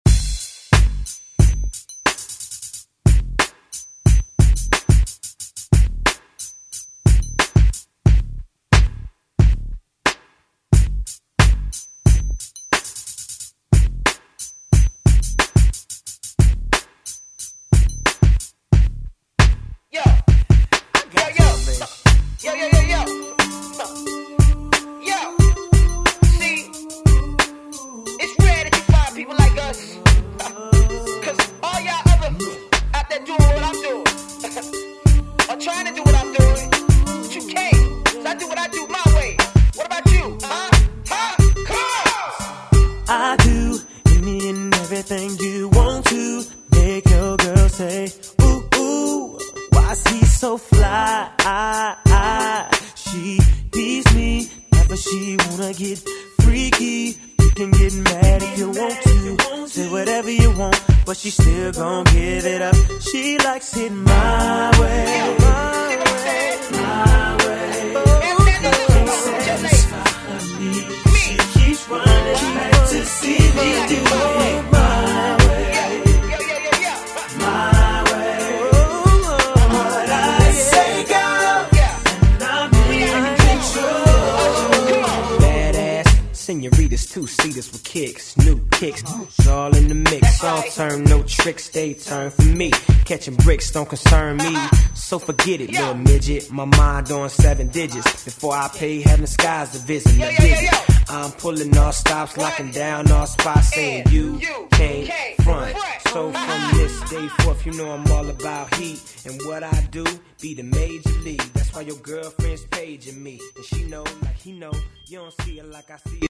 Alternative Indie Rock Music Extended ReDrum Clean 83 bpm
Genre: RE-DRUM
Clean BPM: 83 Ti